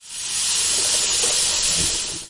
描述：Wave，44.1kHz，16bit，立体声录音设备。
标签： 水槽 抽头 运行
声道立体声